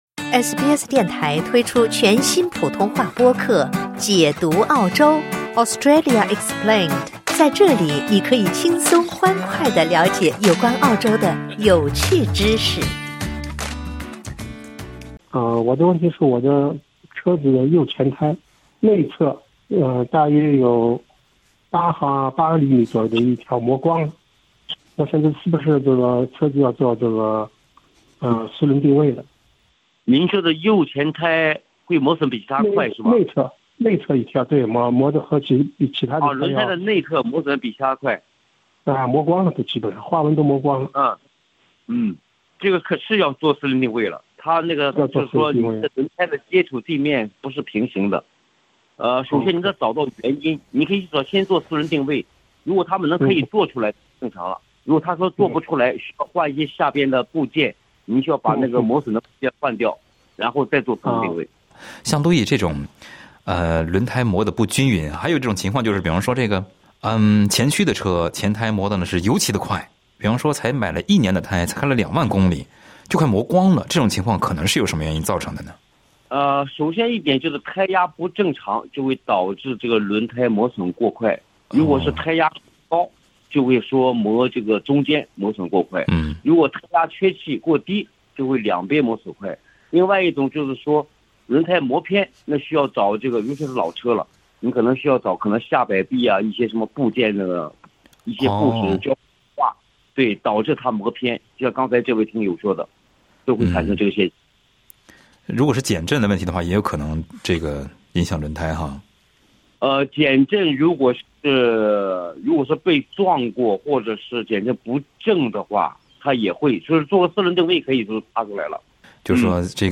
READ MORE 空中汽车诊所 车友咨询，汽车右前轮胎的内侧8厘米出现偏磨，花纹都磨光了，这可能是什么原因造成的？ 在《空中汽车诊所》热线节目中，汽车专业人士就此分享了见解。